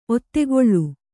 ♪ ottegoḷḷu